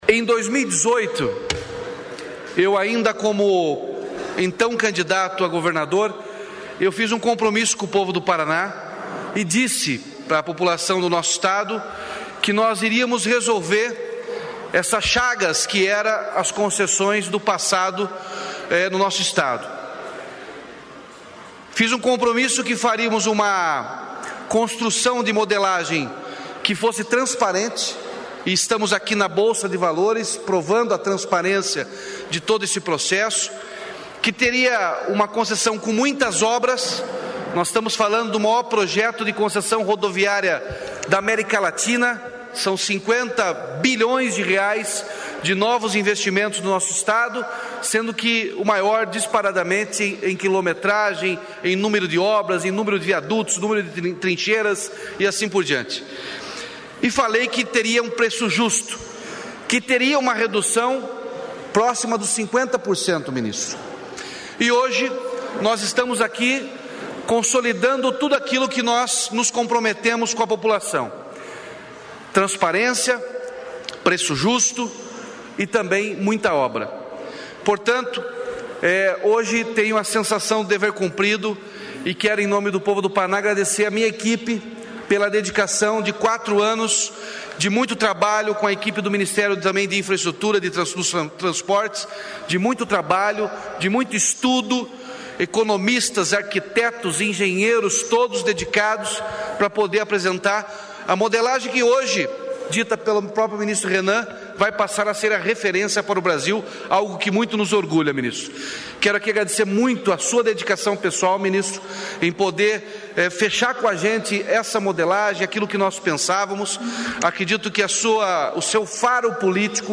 Sonora do governador Ratinho Junior sobre o leilão do Lote 1 do novo pacote de concessões de rodovias do Paraná